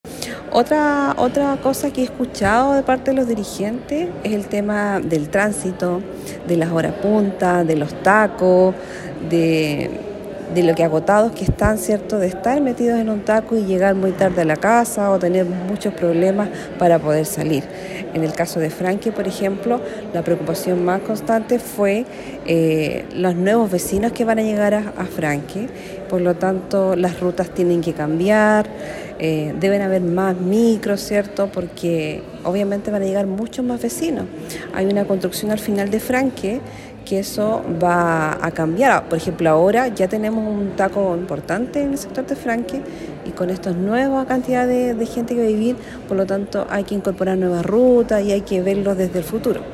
Del mismo modo, la congestión vehicular se torna una problemática para la comunidad, pues en ciertos horarios los tiempos de viaje se tornan excesivamente largos, expresó la Concejala Canales.